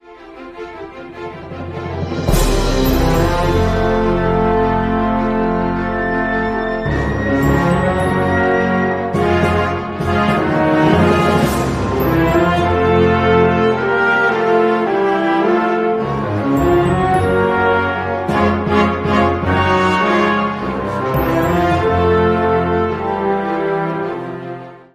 Category: Theme songs